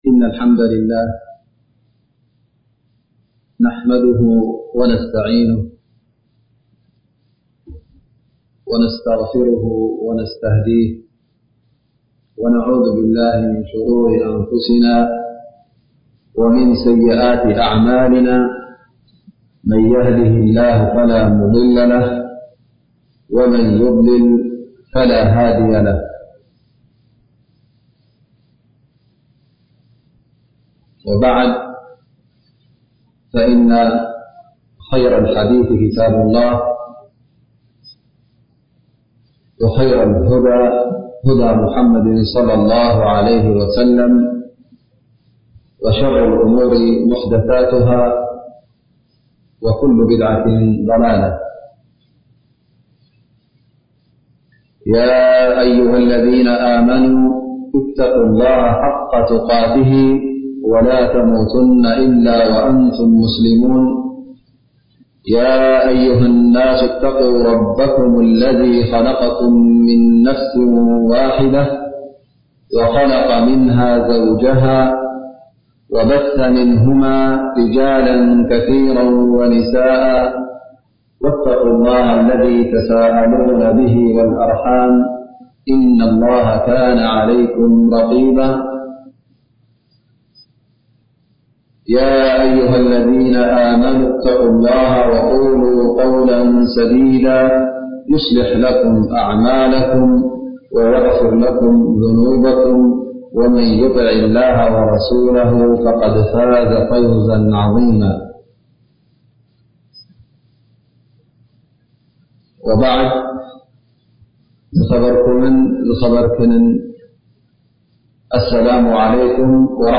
እዚኣ ሙሐዳራ ሲለ መንጎኛ (ተወሱል) ብሸርዒ ፍቑድን ዘይፉቑድ